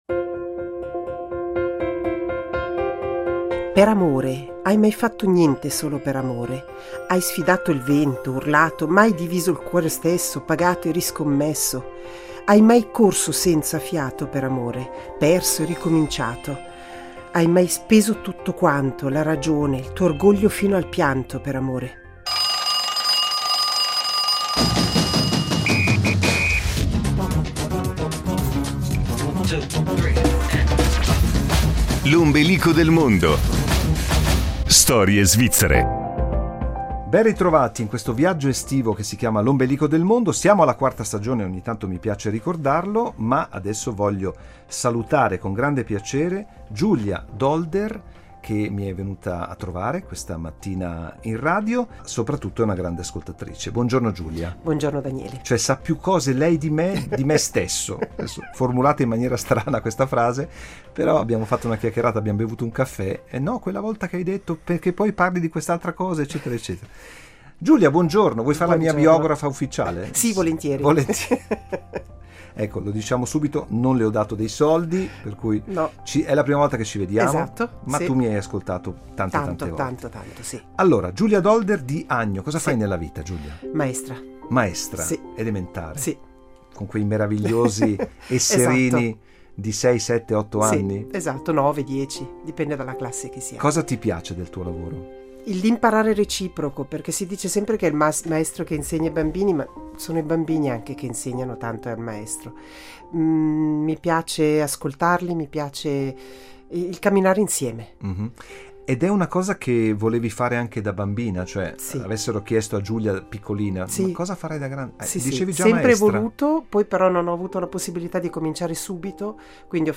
La sua scelta musicale ha premiato Andrea Bocelli, una grande voce che sa trovare le note giuste per celebrare l’amore.